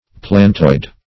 Planetoid \Plan"et*oid\
planetoid.mp3